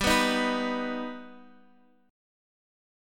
G+ chord